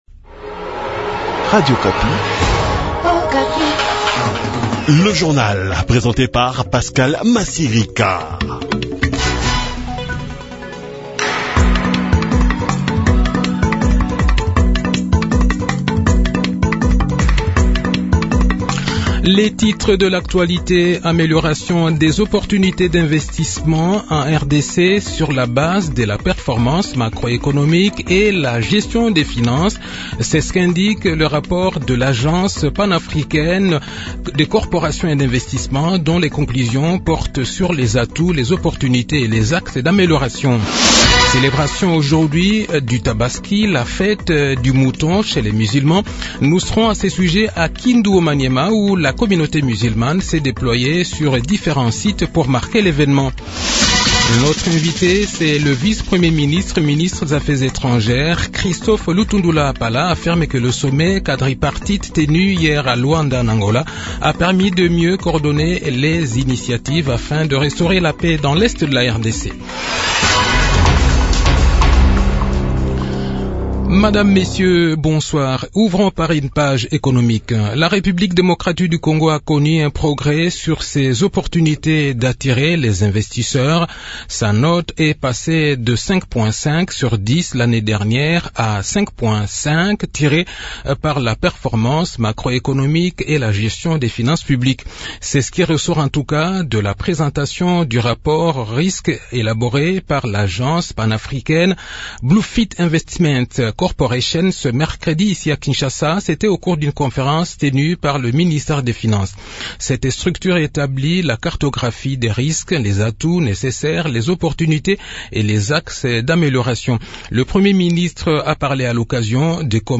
Le journal de 18 h, 28 Juin 2023